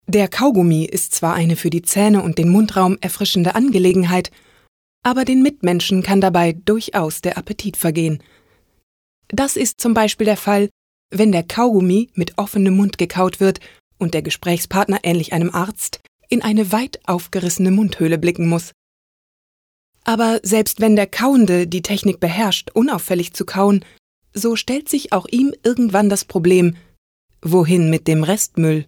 Deutscher Sprecher, Off-Sprecher, mittlere bis tiefe Stimme, Hörspiel, Hörbuch, Voice over, Audiodeskription (Little Dream Entertainment, ARD, arte)
norddeutsch
Sprechprobe: eLearning (Muttersprache):